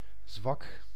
Ääntäminen
US : IPA : [ˈfi.bəl]